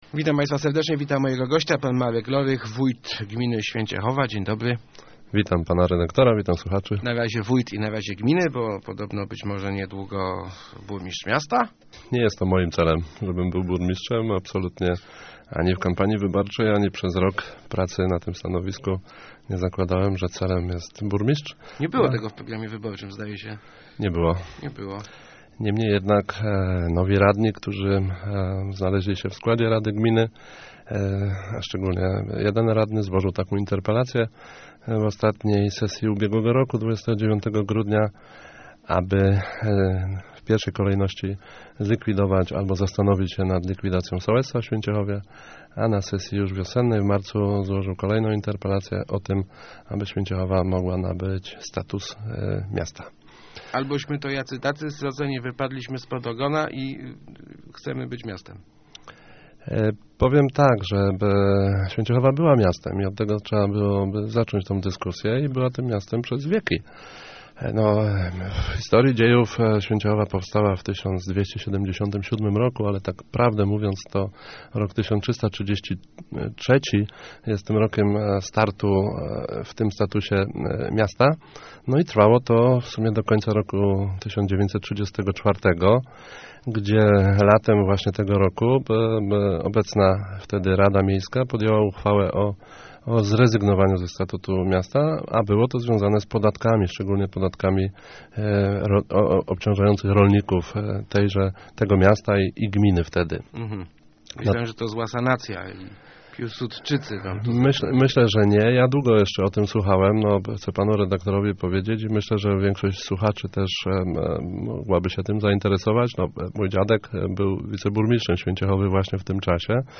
Lepiej być silną wsią, niż słabym miastem - mówił w Rozmowach Elki wójt Święciechowy Marek Lorych. Przyznaje on, że odzyskanie praw miejskich wydaje się naturalnym procesem i ma swoje zalety, jednak decyzja musi być solidnie przemyślana.